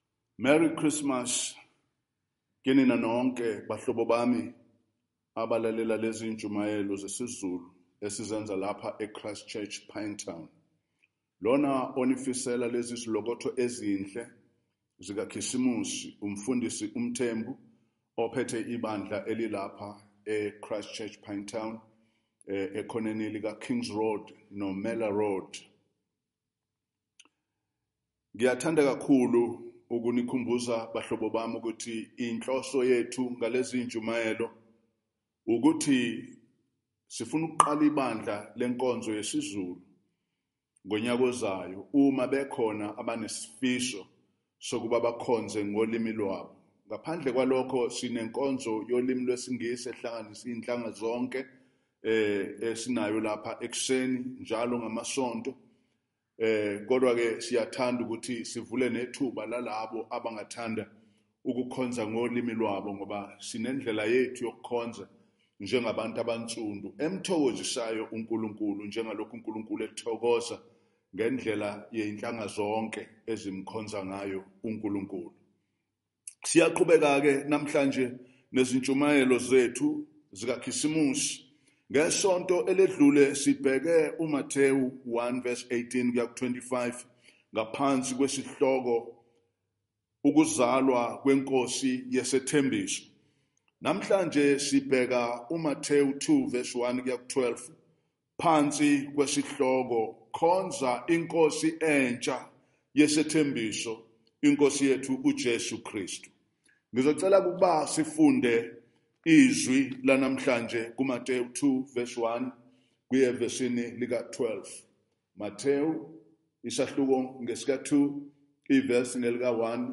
Christmas Day Zulu Sermon – Khonza iNkosi entsha yesethembiso
Passage: Matthew 1:18-25 Event: Zulu Sermon